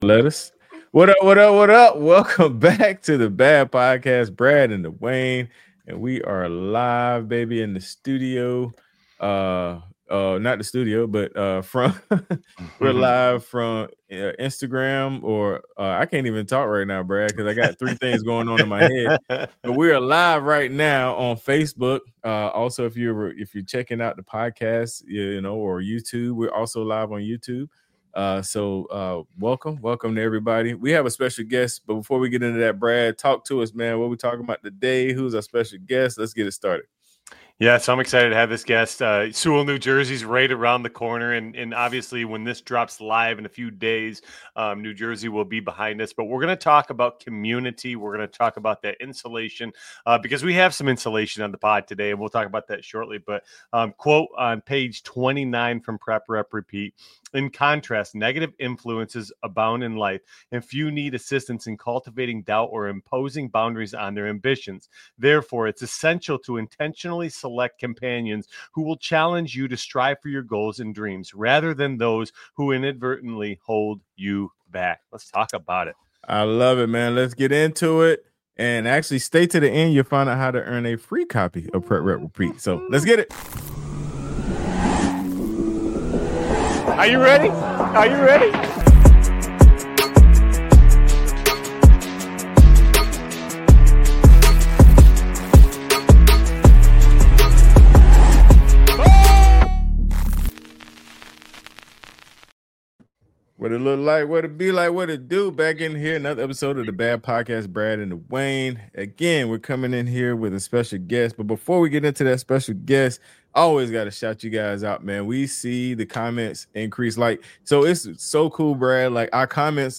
Expect laughs, life lessons, and relatable moments about community, overcoming doubt, and chasing dreams (even in midlife!)